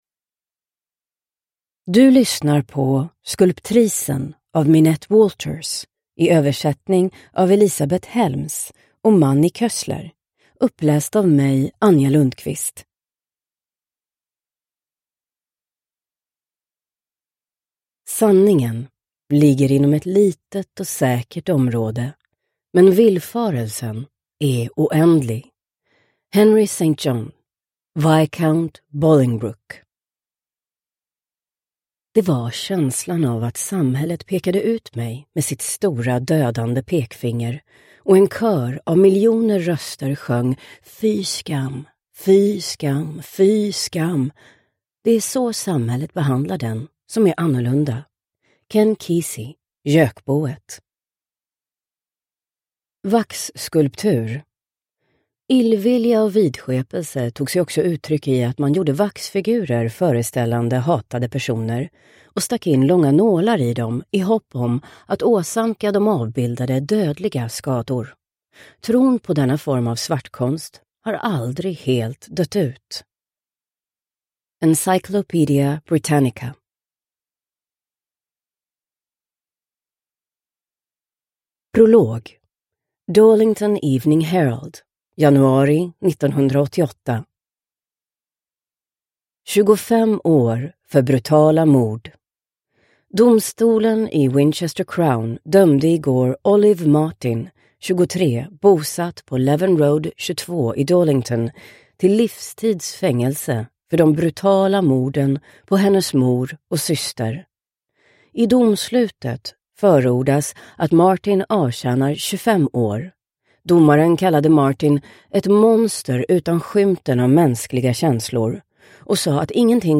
Skulptrisen – Ljudbok – Laddas ner